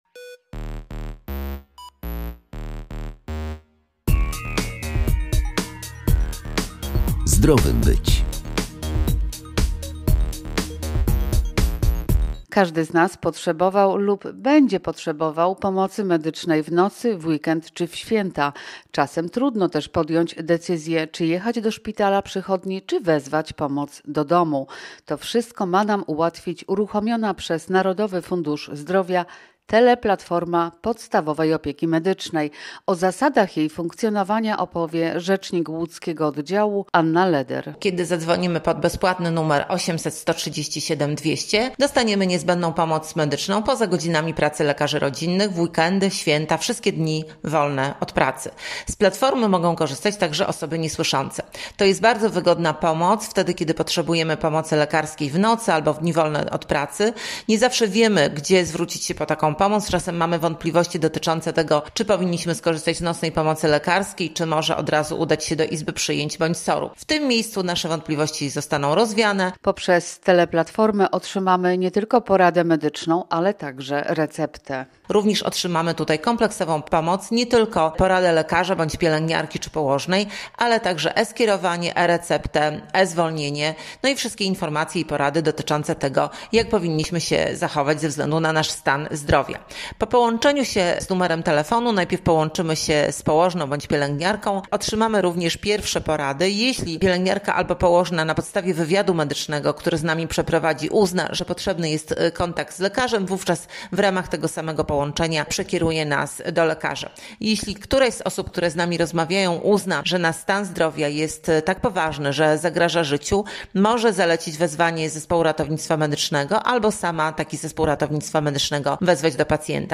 W magazynie zdrowym być jeszcze o nowej możliwości wypełnienia kwestionariusza online przed szczepieniem.